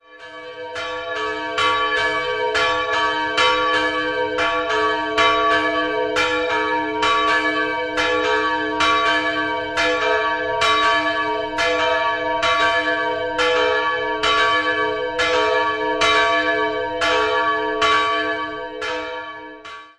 Beschreibung der Glocken
Der Hellring beginnt am Donnerstag und dauert bis Montag. 2-stimmiges Geläute: d''-g'' Die kleine Glocke wurde von einem Gießer "Otto" im Jahr 1297 (!) gegossen, die größere stammt von Hans Graf (Landshut) aus dem Jahr 1521.